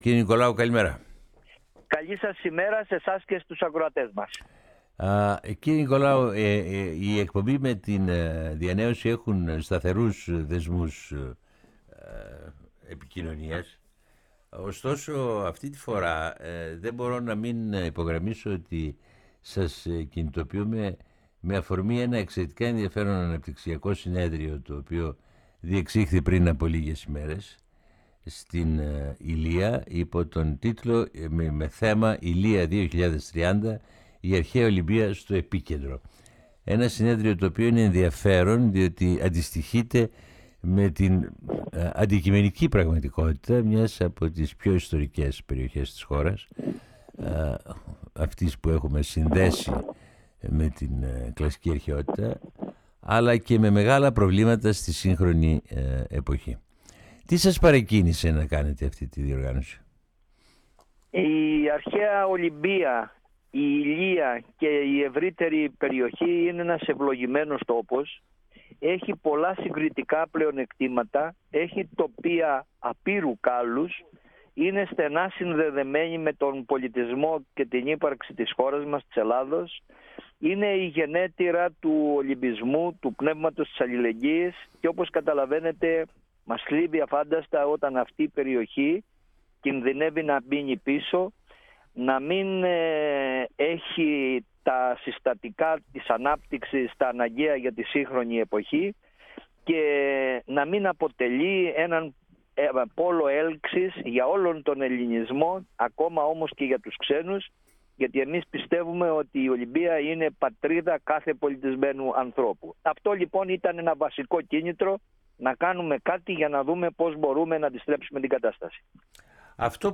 φιλοξενήθηκε σήμερα στην εκπομπή “Η Ελλάδα στον κόσμο”